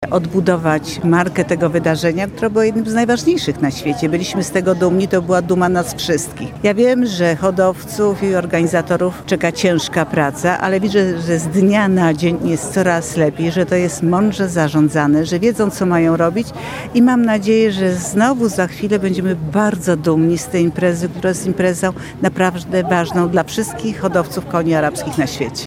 – Wierzę, że hodowcom uda się odbudować markę polskiego konia – mówiła dziś (08.08) w Janowie Podlaskim podczas Dni Konia Arabskiego marszałek Senatu Małgorzata Kidawa-Błońska.